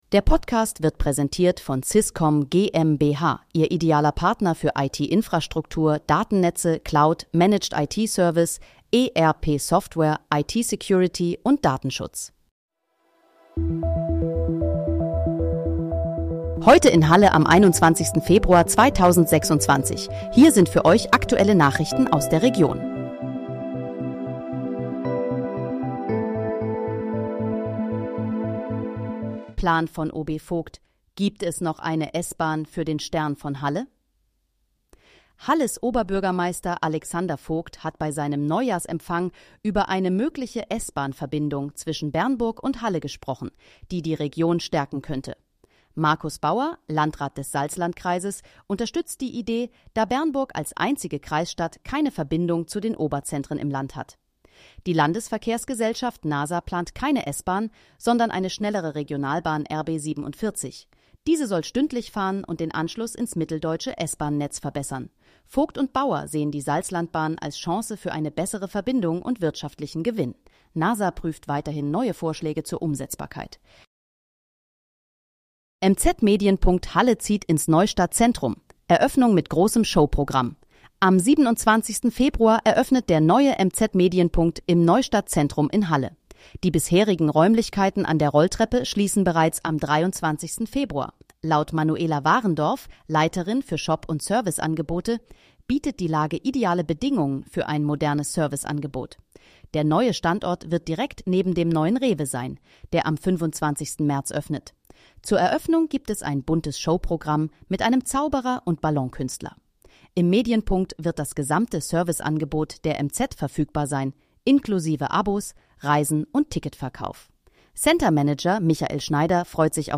Heute in, Halle: Aktuelle Nachrichten vom 21.02.2026, erstellt mit KI-Unterstützung
Nachrichten